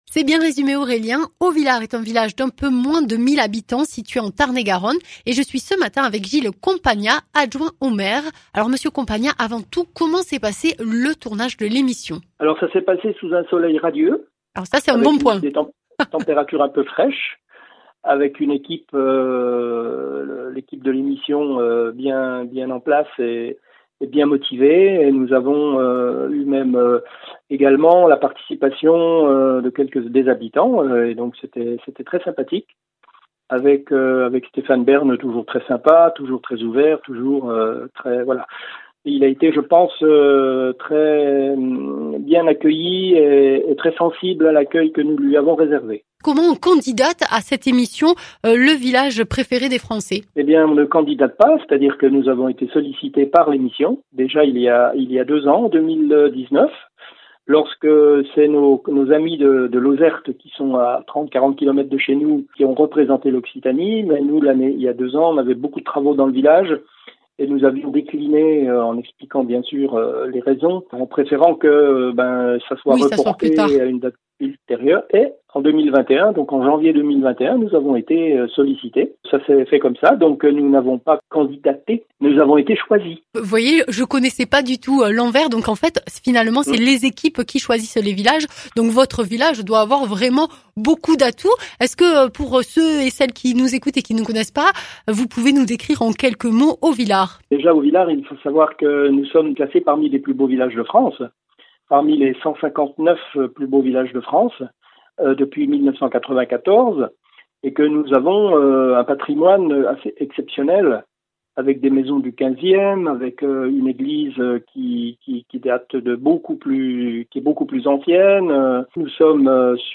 Interviews
Invité(s) : Gilles Compagnat, adjoint au maire à Auvillar (Tarn et Garonne)